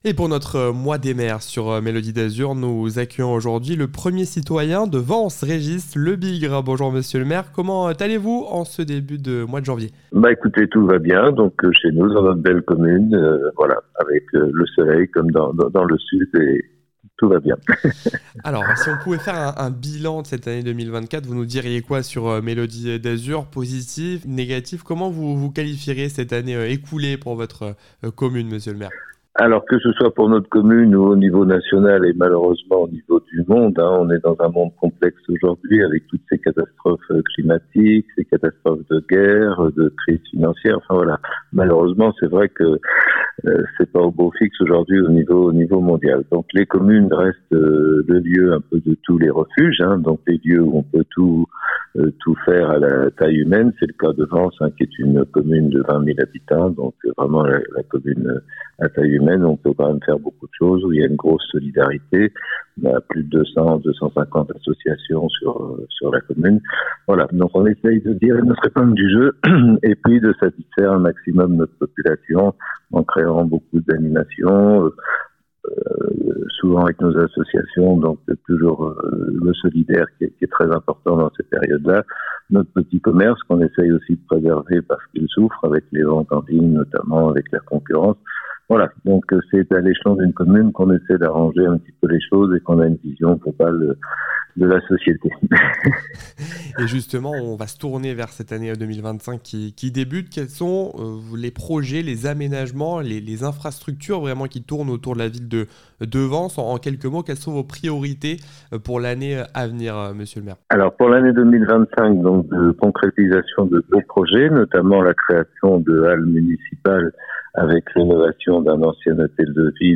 Interview des Maires - Épisode 1 : Vence avec Régis Lebigre
interview-des-maires-episode-1-vence-avec-regis-lebigre.mp3